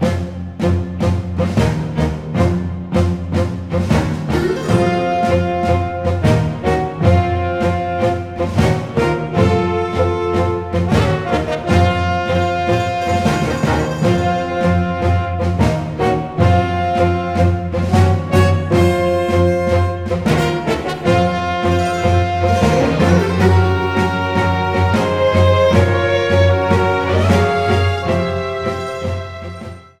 Source Game rip
Edited Trimmed to 30 seconds and edited to fade out